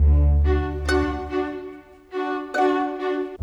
Modern 26 Strings 02.wav